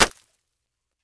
Knife_Generic1.wav